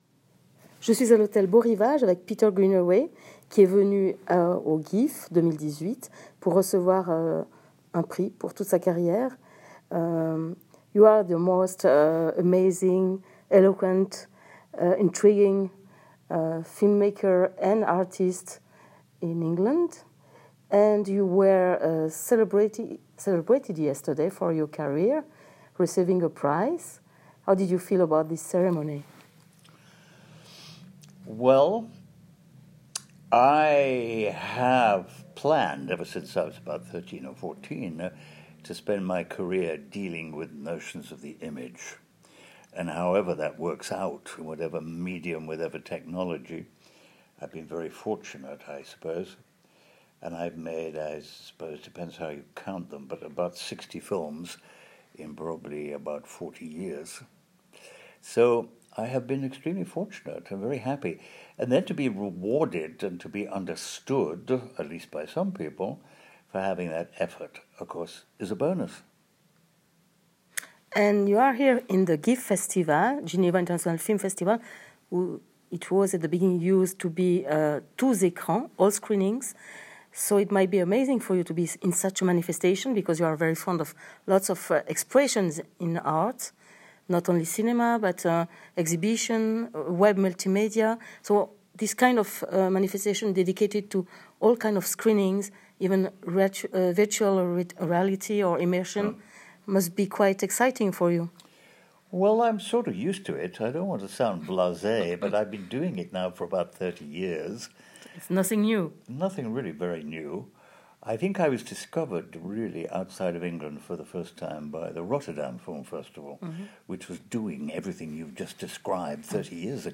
Rencontré à l’hôtel Beau-Rivage, à Genève, dans la suite qu’occupait l’Impératrice Sissi quand elle était à Genève, Peter Greenaway a avoué être ému de s’y trouver en songeant à l’installation des Stairs qu’il avait organisé à Genève.
Le ton de l’entretien est donné qui se fera entre humour pince-sans-rire et réflexions très sérieuse sur la situation actuelle des artistes et des ressortissants britannique depuis le Brexit.